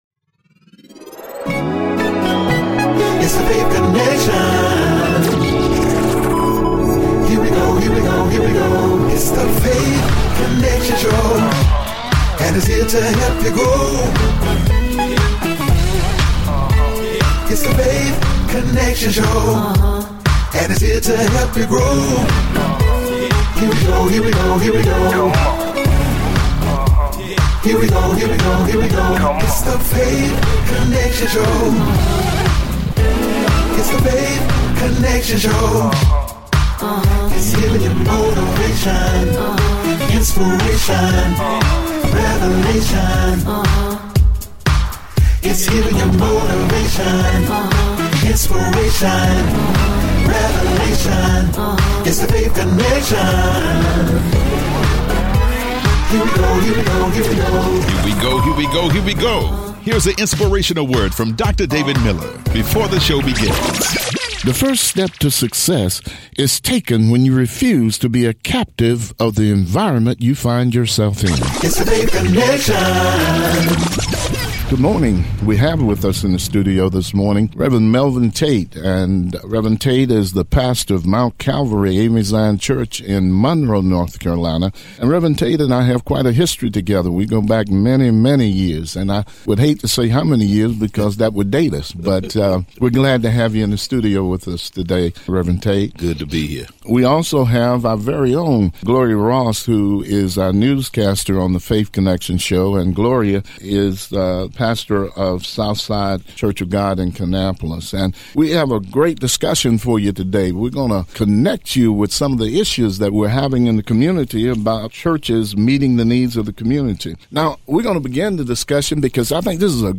Weekly talk show in which we interview people that have gone through tremendous struggles, have overcome and been able to succeed.